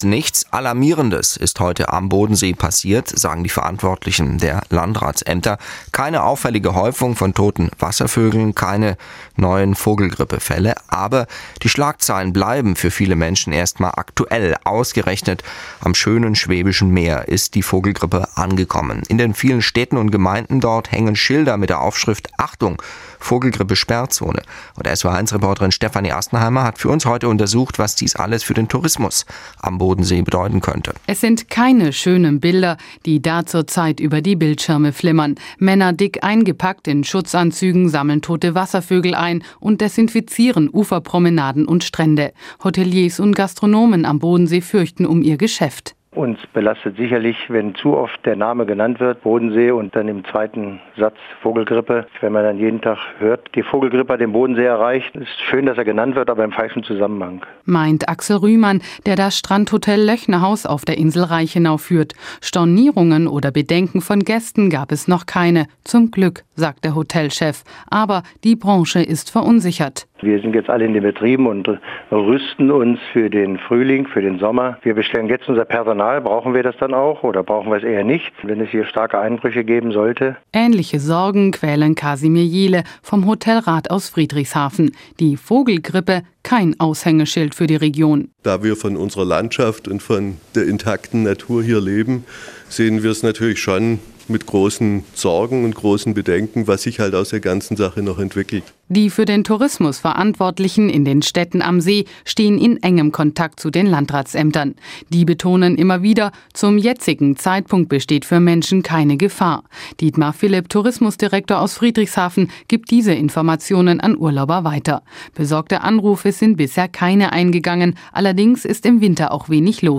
Im März 2006 berichtet SWR1 Baden-Württemberg über die Sorgen am Bodensee, dass die Vogelgrippe die Urlauber fern halten könnte: